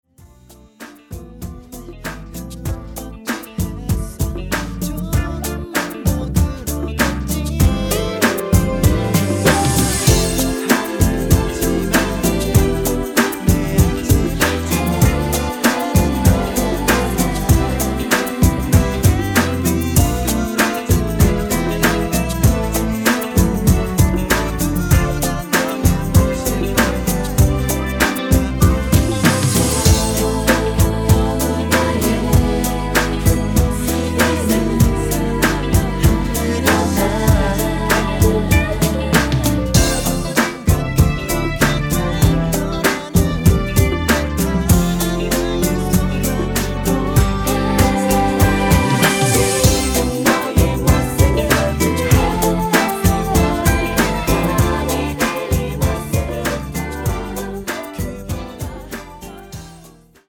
음정 원키 3:58
장르 가요 구분 Voice MR